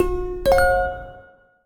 Index of /phonetones/unzipped/BlackBerry/Priv/notifications